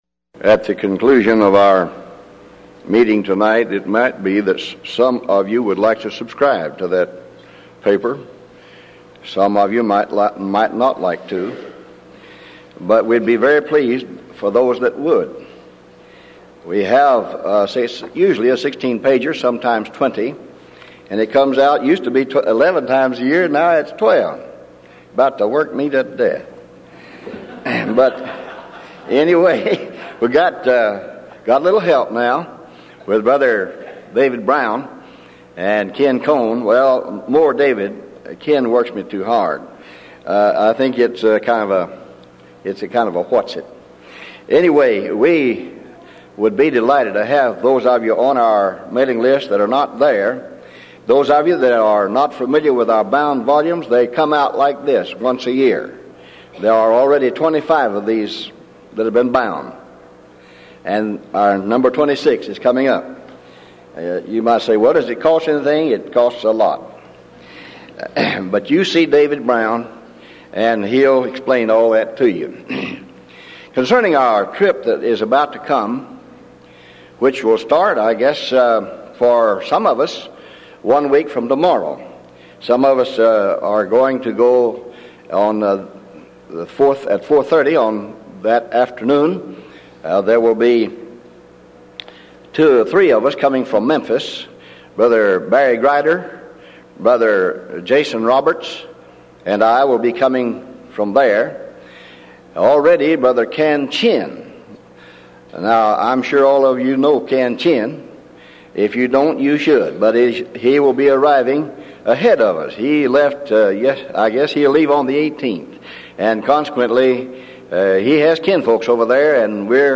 Event: 1995 Denton Lectures
lecture